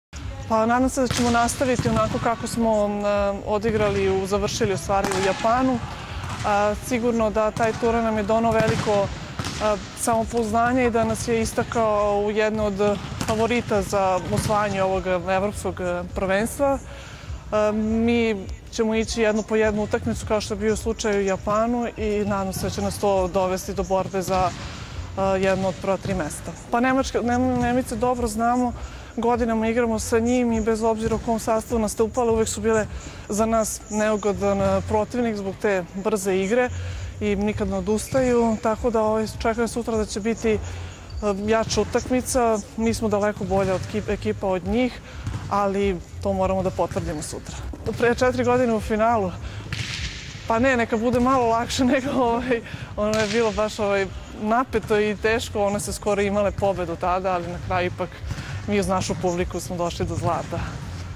IZJAVA JELENE NIKOLIĆ